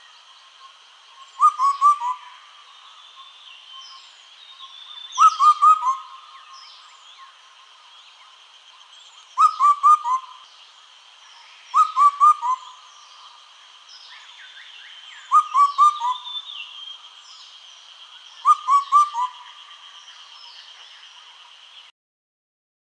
四声杜鹃鸟独特的四声叫声
四声杜鹃鸟鸣声